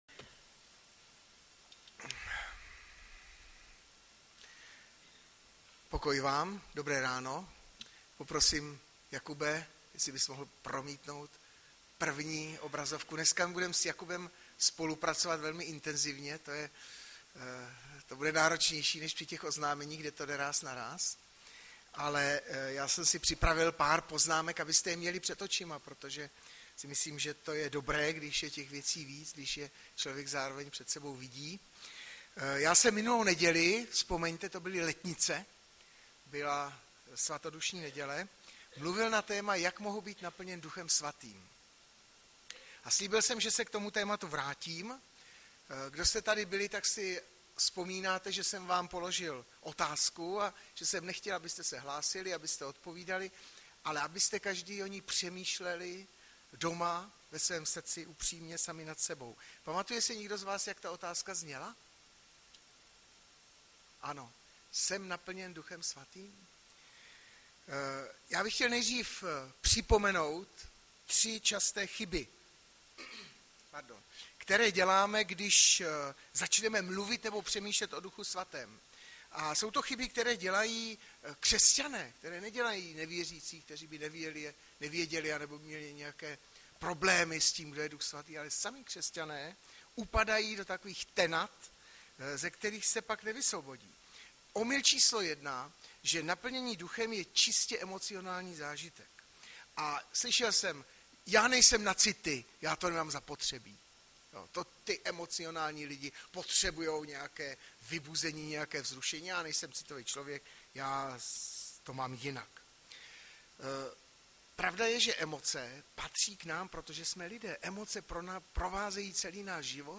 Kázání